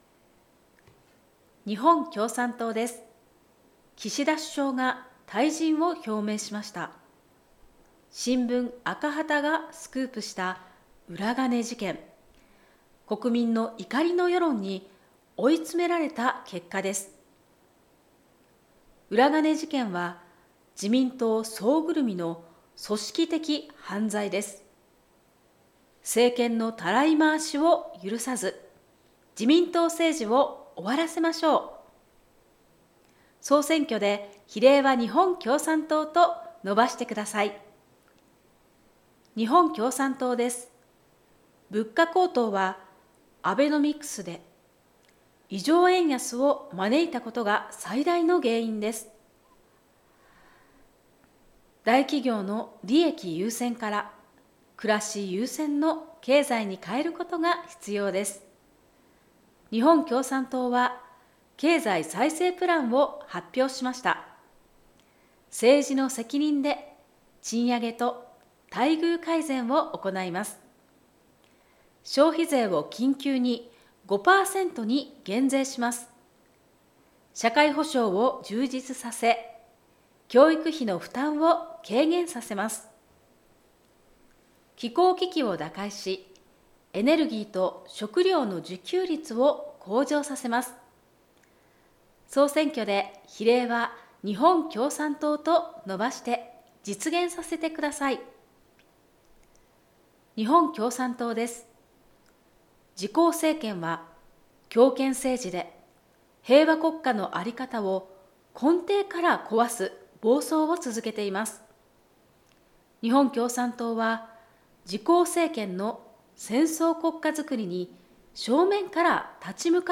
宣伝カースポット8月20日作成音声データ